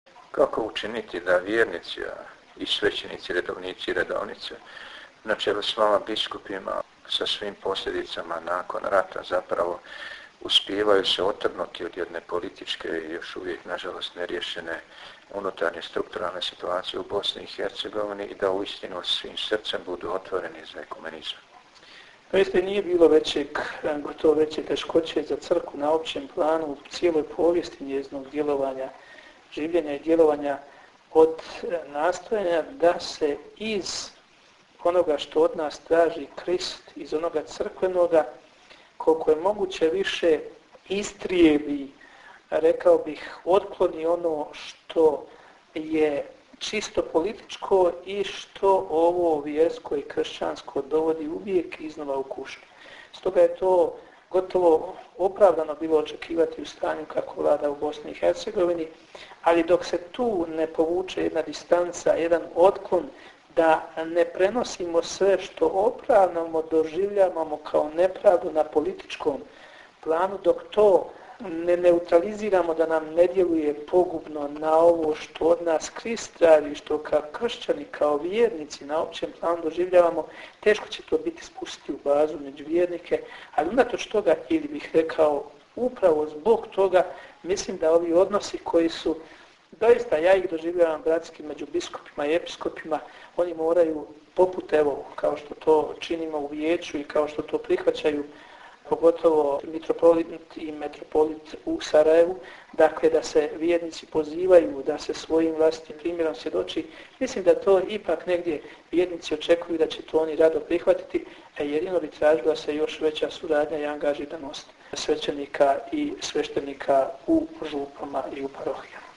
Razgovor s biskupom Perom Sudarom